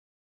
spinnerspin.wav